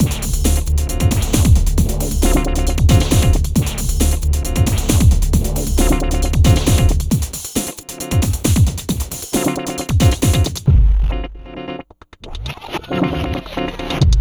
31 Futurefunk-e.wav